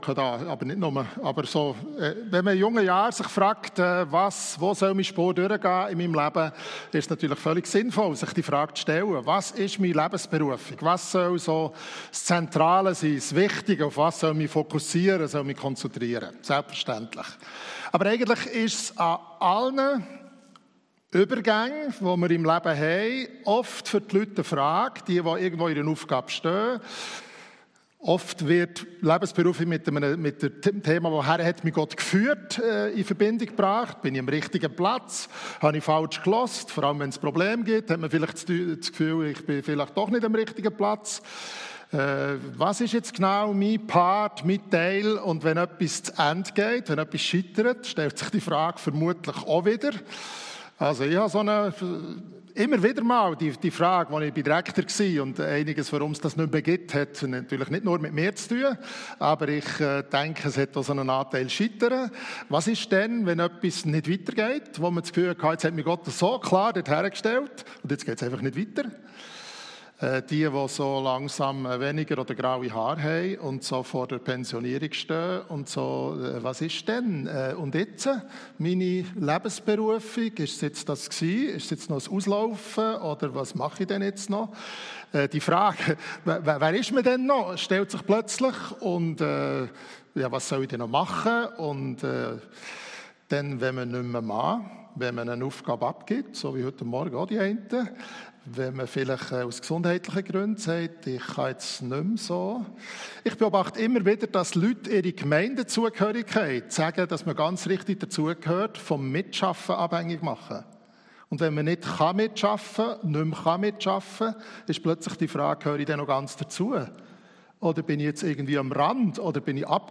Gottesdienst
Predigten